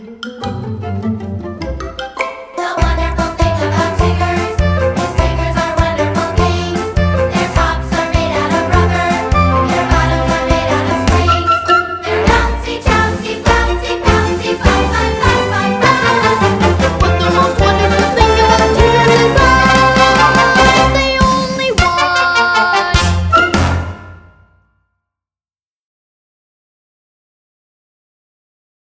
• September 3rd – In rehearsal the kids will all learn a group dance and a short singing solo (the “audition cut”, shared below.)